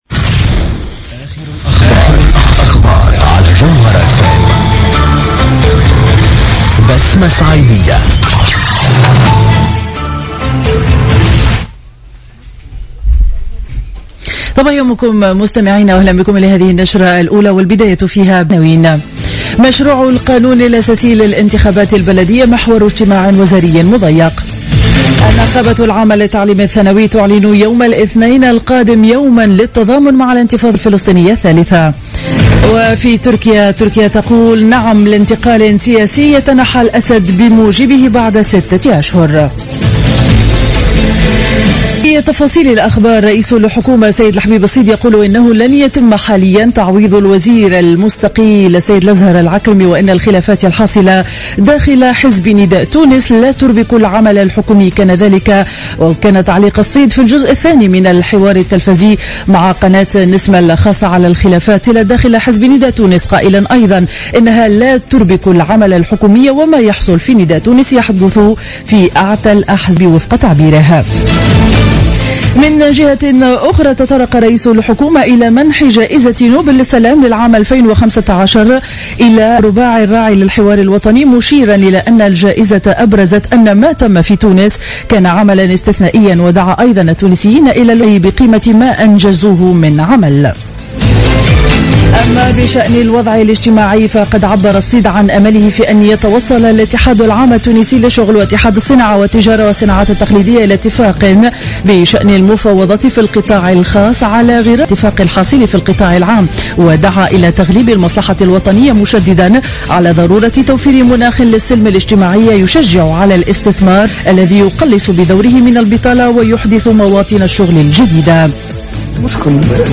نشرة أخبار السابعة صباحا ليوم الأربعاء 21 أكتوبر 2015